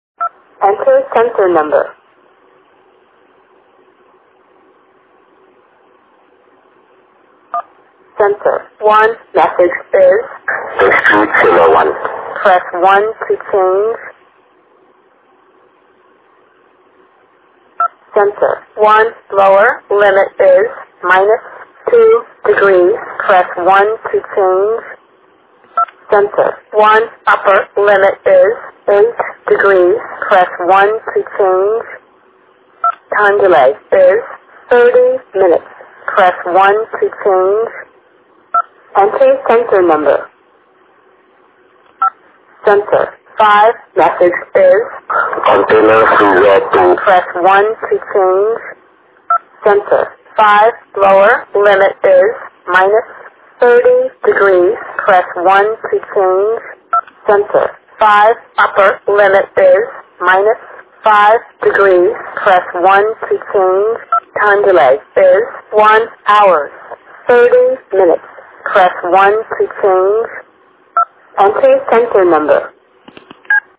VM500-5 Voice Demonstration